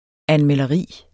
Udtale [ anmεlʌˈʁiˀ ]